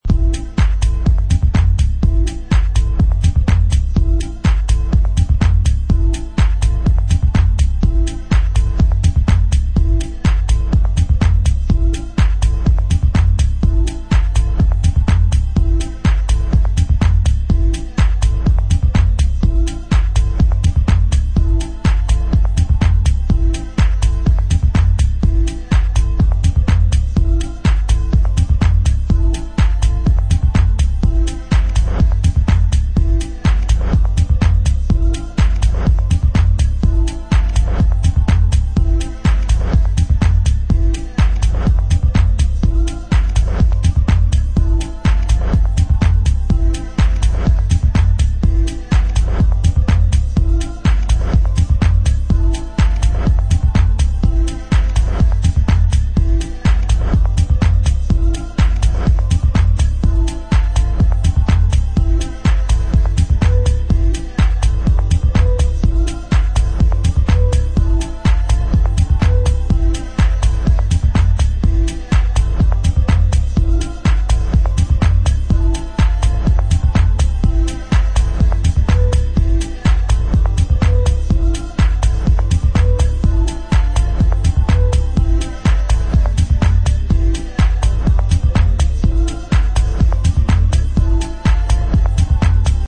Versatile tracks that go from deep electronica to... more...
Electronix Techno